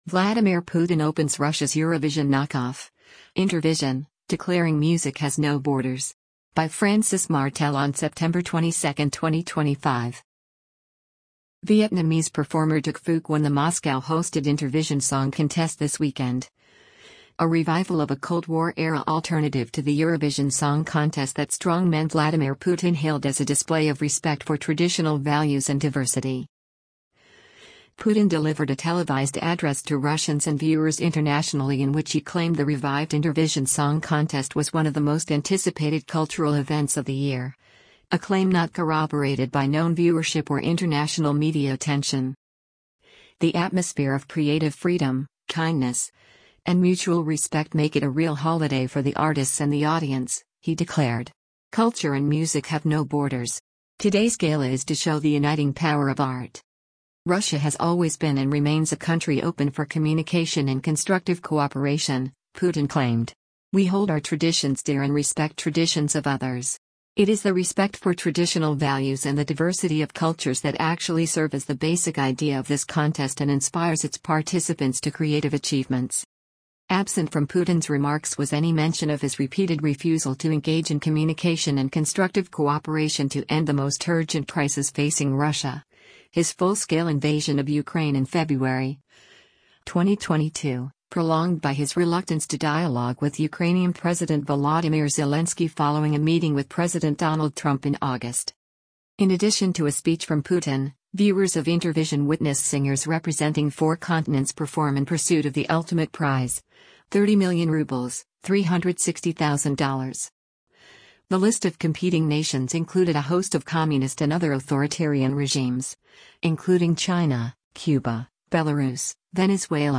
Putin delivered a televised address to Russians and viewers internationally in which he claimed the revived Intervision Song Contest was “one of the most anticipated cultural events of the year,” a claim not corroborated by known viewership or international media attention.